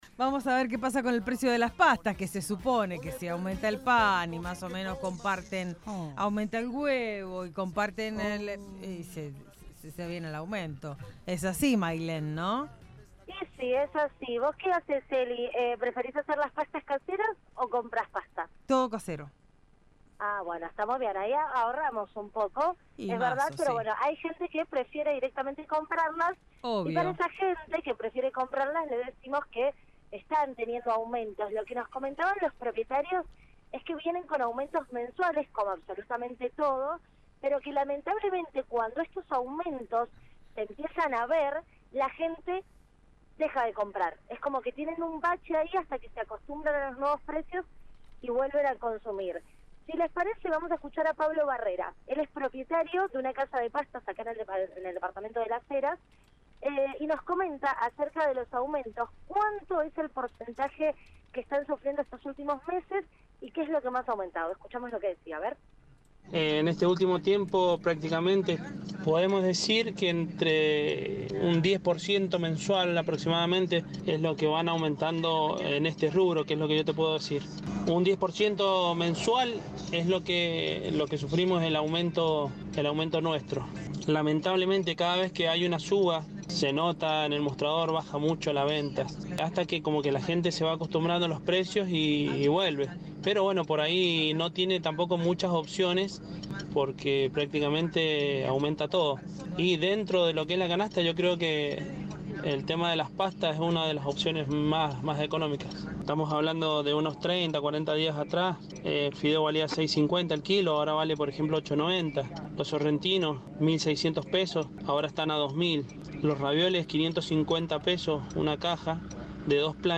Móvil de LVDiez desde fábrica de pastas de Las Heras